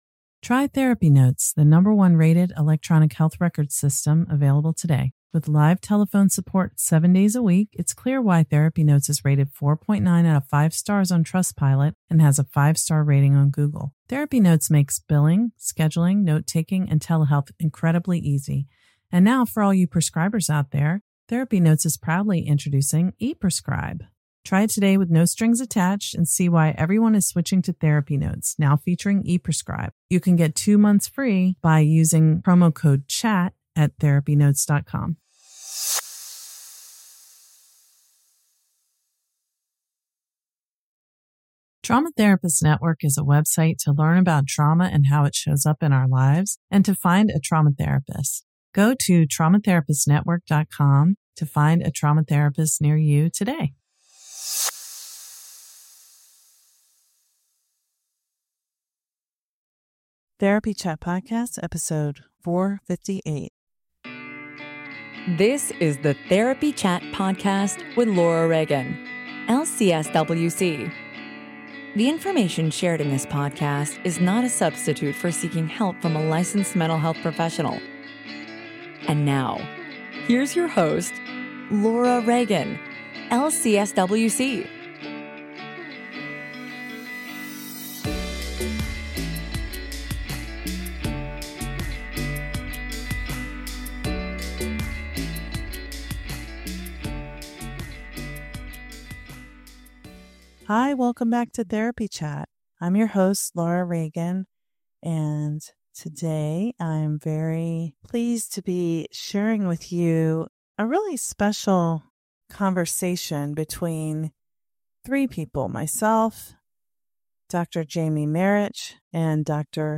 This week we have a conversation with two guest that I hope you'll find interesting whether you identify as a therapist, a human with complex PTSD/developmental trauma, part of a system of multiples or someone with a dissociative disorder, or if you are anyone who's curious about parts work and your inner world.